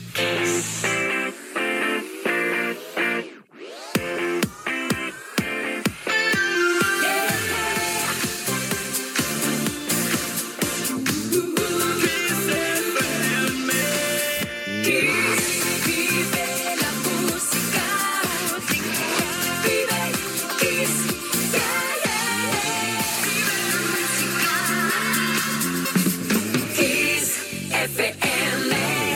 Indicatiu de l' emissora